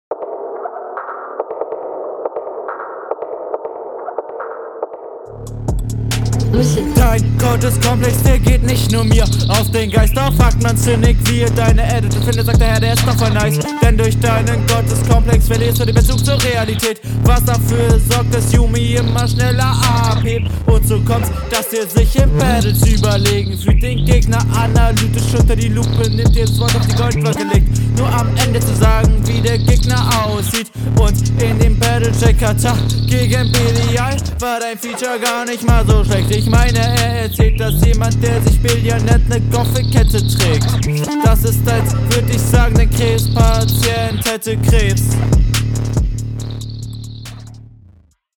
ich versuch mal ein bisschen auf flow einzugehen weil du da ja grade viel ausprobierst …